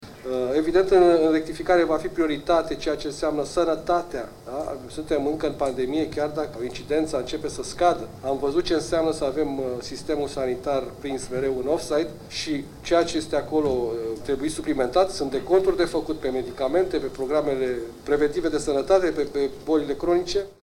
Rectificarea bugetară va avea loc vineri noaptea, a anunțat la audirea din Parlament, viitorul ministru al Finanțelor, Adrian Câciu.